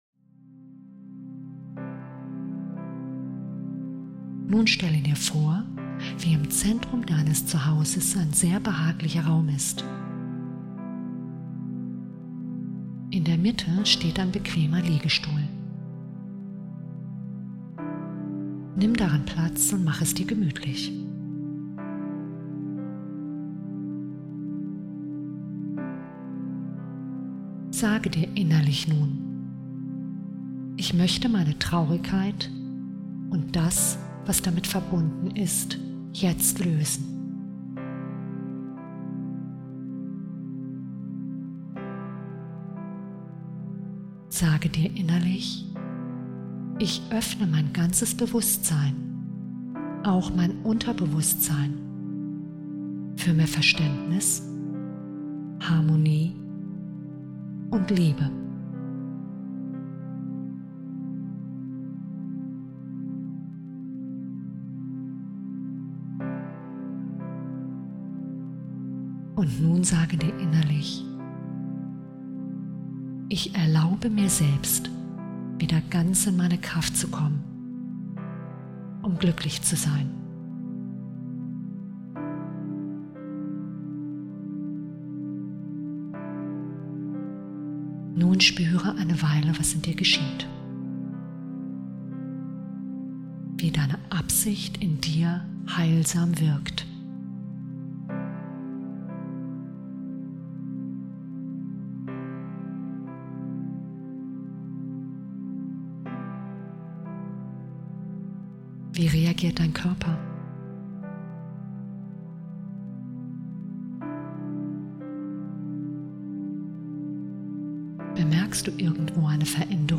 Geführte Meditation